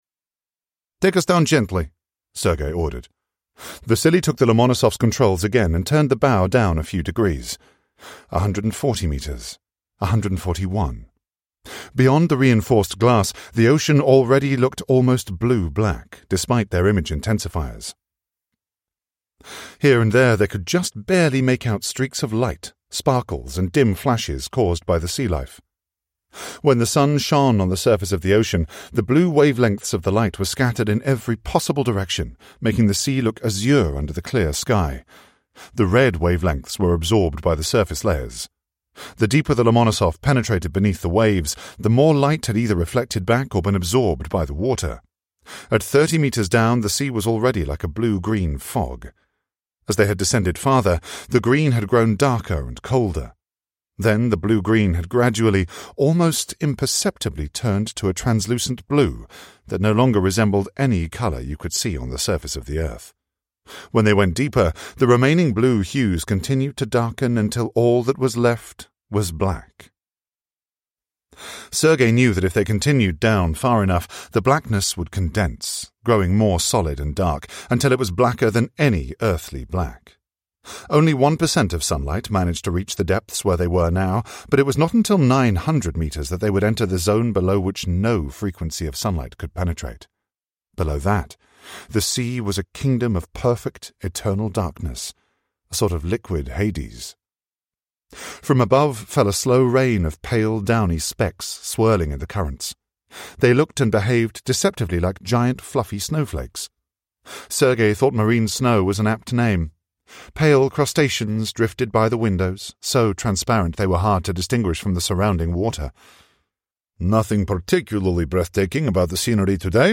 The Sands of Sarasvati – Ljudbok